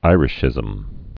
(īrĭsh-ĭzəm)